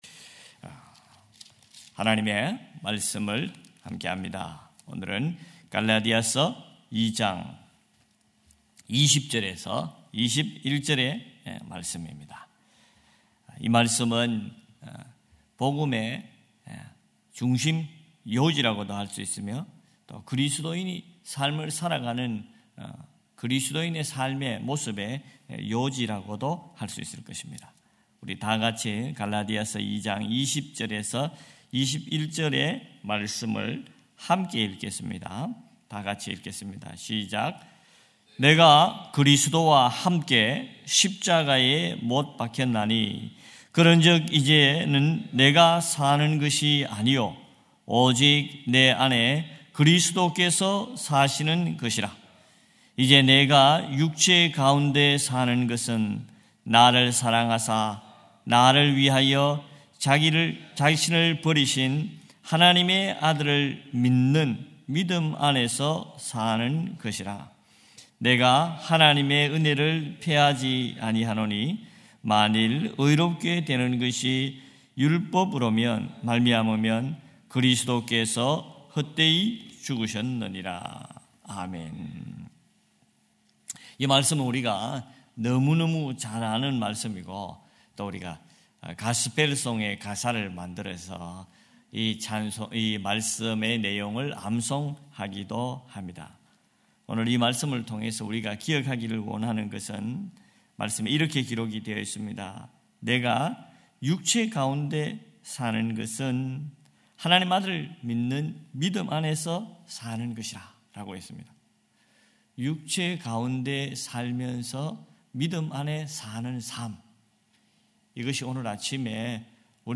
June 28 2025 새벽예배 갈라디아서 2장 20-21절